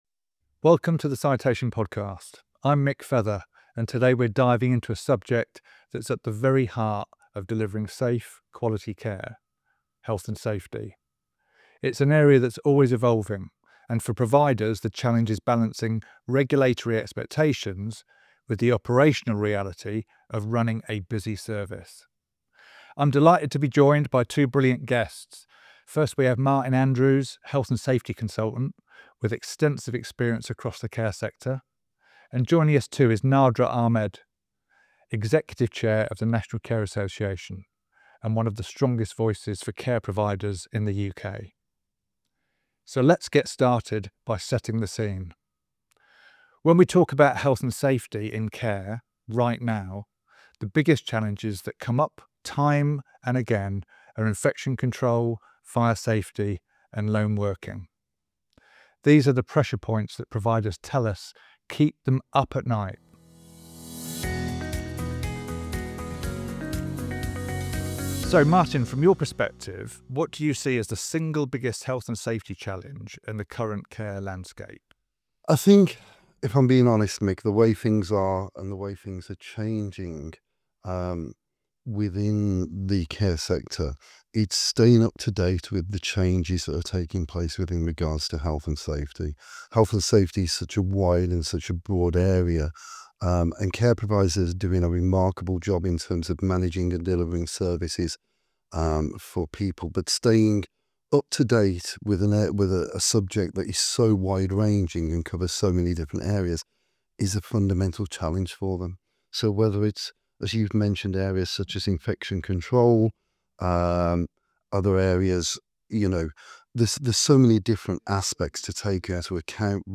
A practical discussion on Health & Safety challenges in care. Learn how to manage risk, inspections and compliance without overwhelming staff.